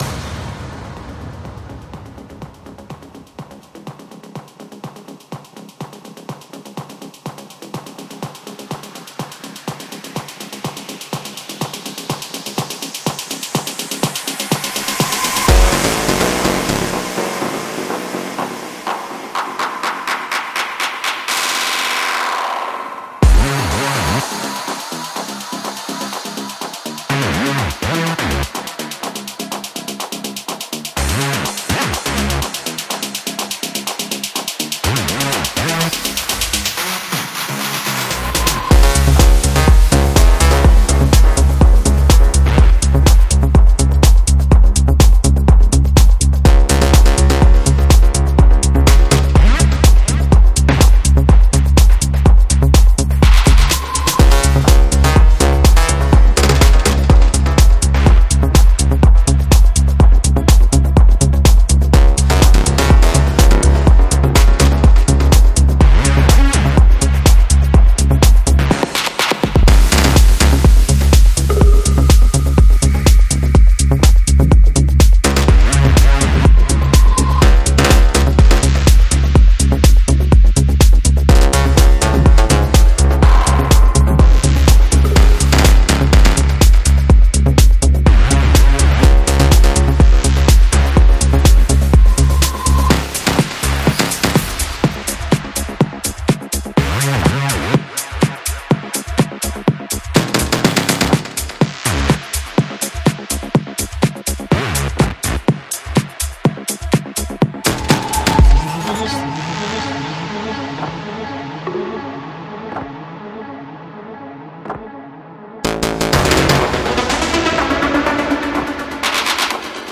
Genre: Techno